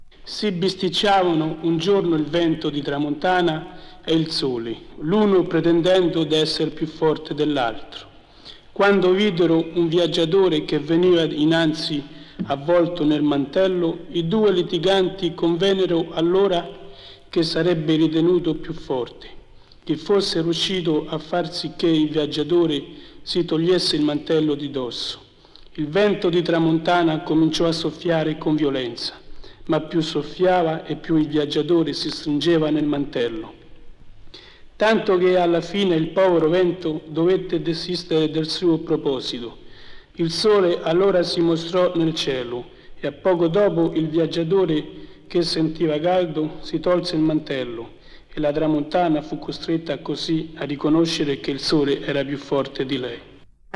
Campione delle registrazioni audio dell’italiano regionale registrate da Carlo Tagliavini (1965) | Korpus im Text
Favola_Roma.wav